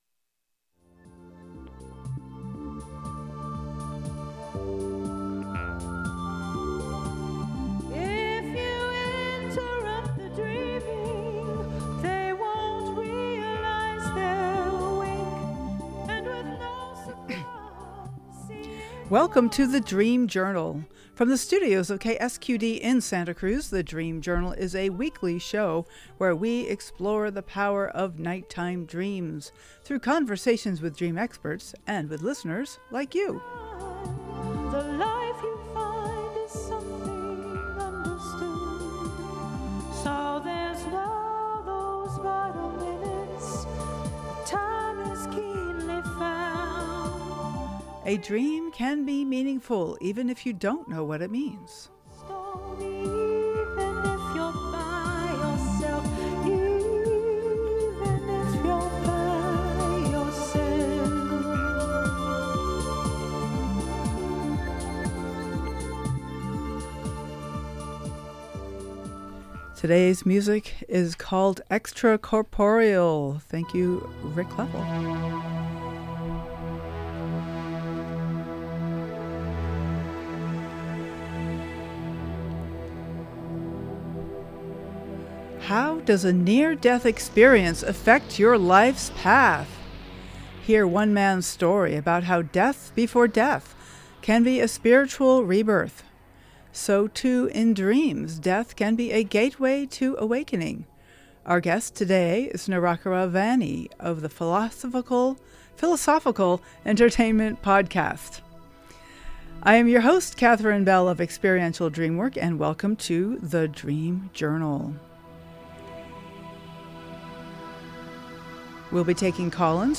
Listeners also called in to share their own experiences, including near-death through plant medicine and powerful early childhood encounters with mortality.